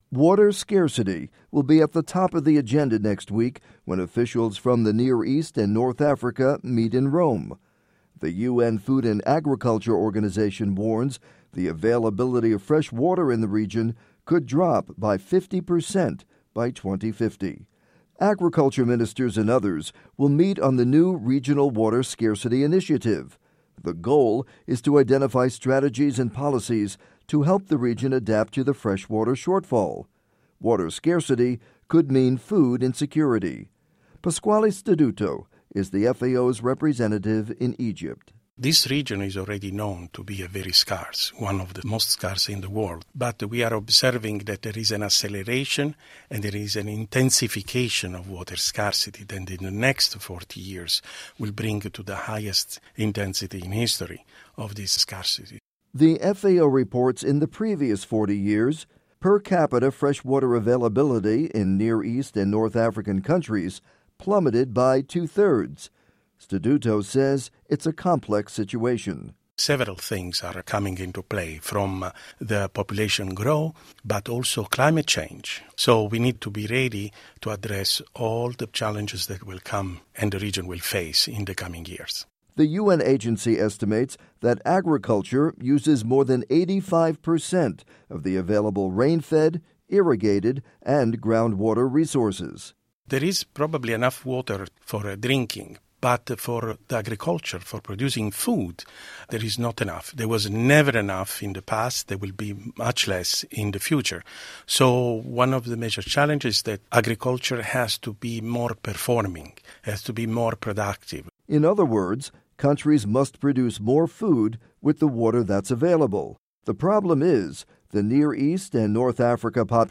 report on Near East / North Africa water scarcity